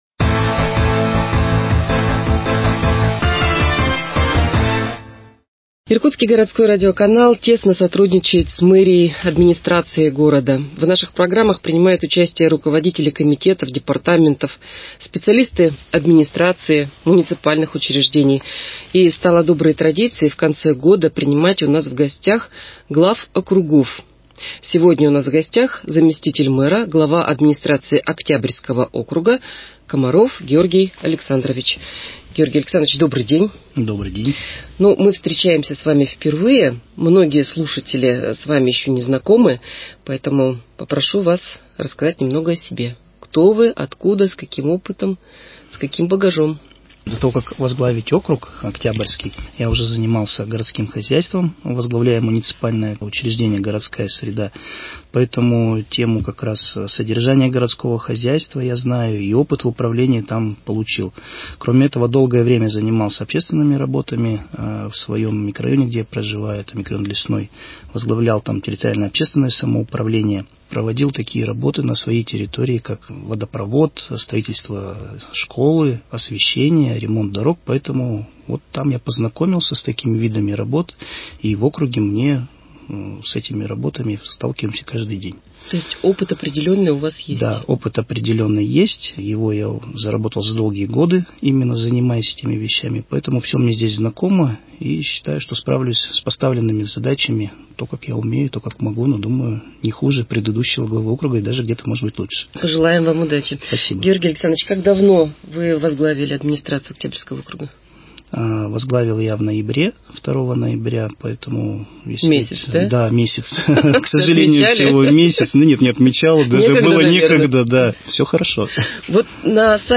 Передача «Актуальное интервью». Месяц в новой должности – с какими проблемами пришлось столкнуться и первые успехи.